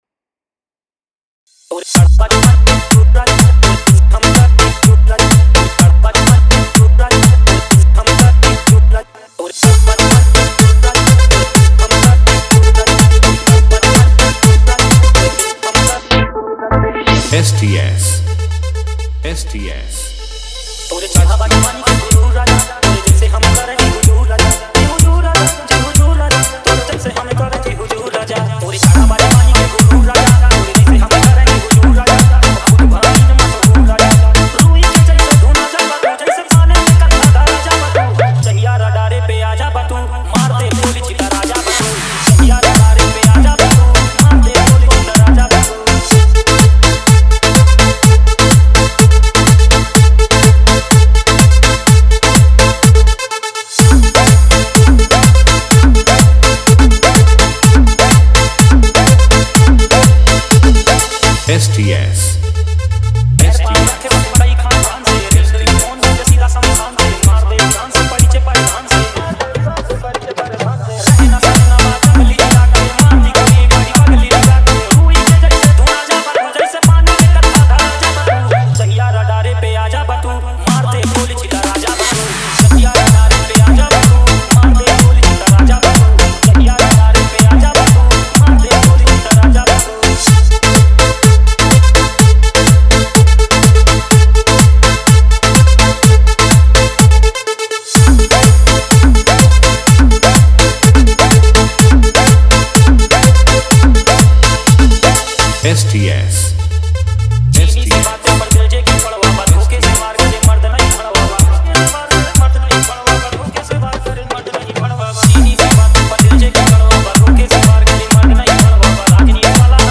Bhojpuri DJ remix mp3 song
Latest Bhojpuri DJ remix song